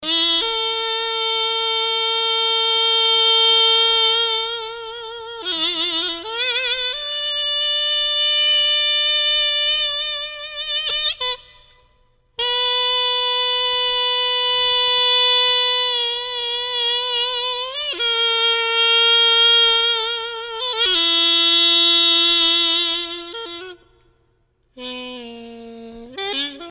Musique de cour - Hautbois piri